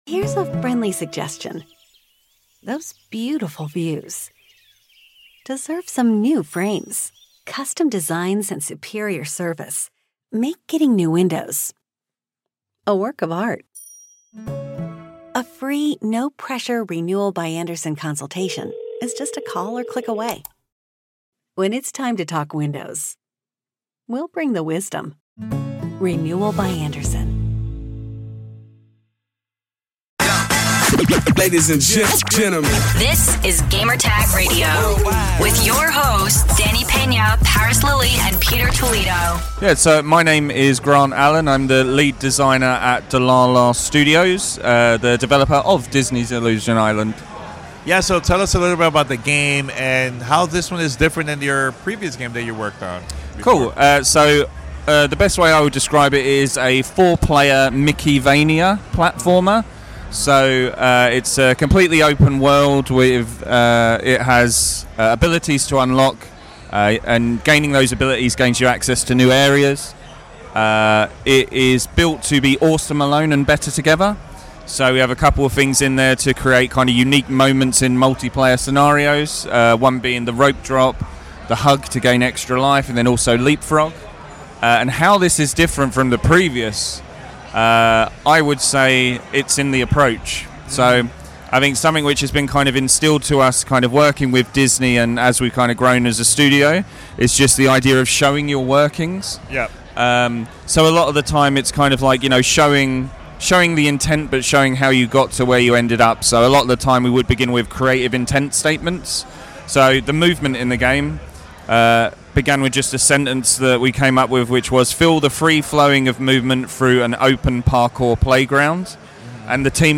SGF23: Disney Illusion Island Interview